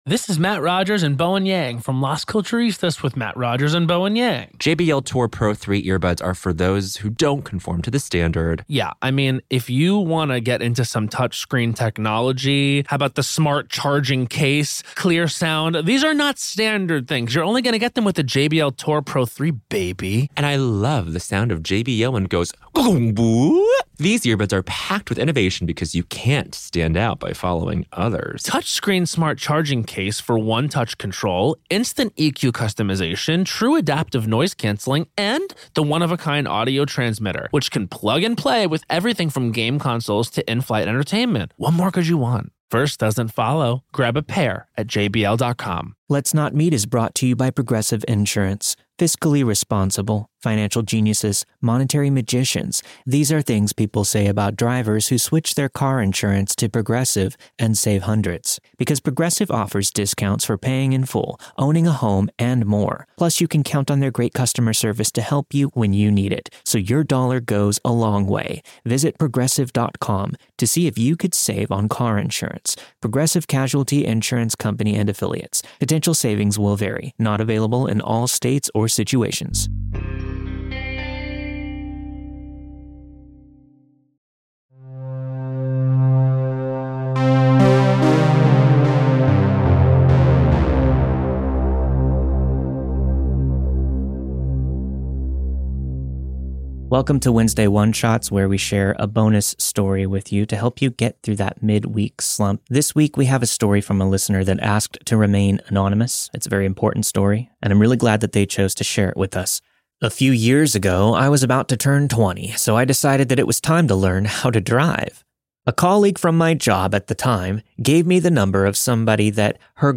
The story you've heard this week was narrated and produced with the permission of its respective author.